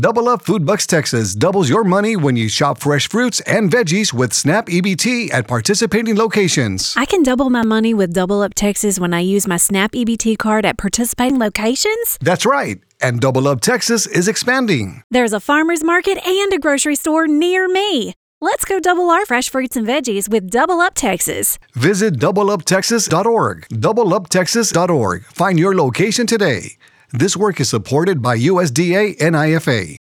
Competitive Messages: Radio Ad Production
Our radio campaign struck a balance between these extremes—bold, immediate, and attention-grabbing, yet grounded in a positive, inclusive tone that resonated with listeners.